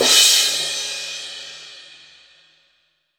Index of /90_sSampleCDs/East Collexion - Drum 1 Dry/Partition C/VOLUME 002
CRASH030.wav